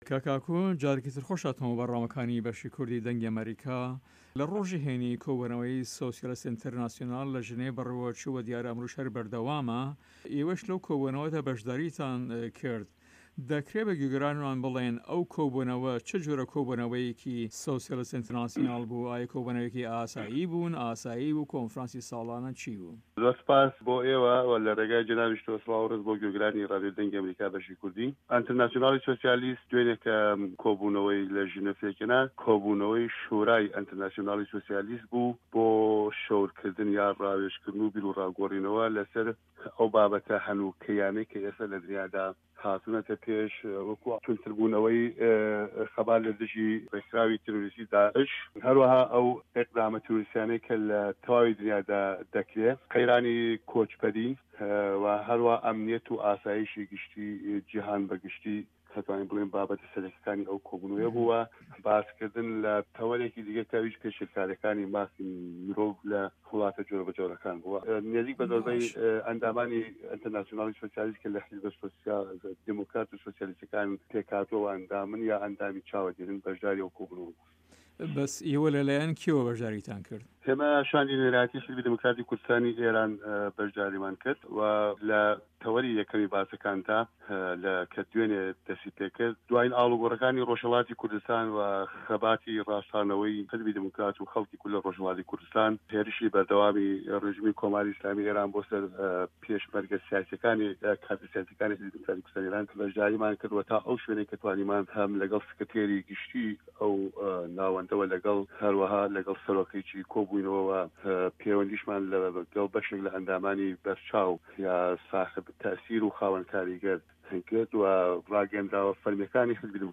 Ainterview